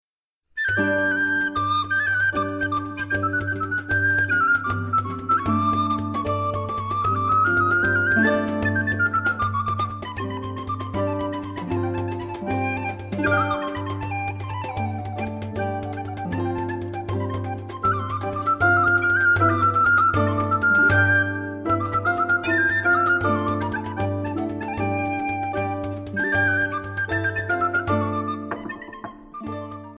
Qiyan's Tapestry: a programme based on medieval music.